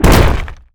rock_impact_spike_trap_01.wav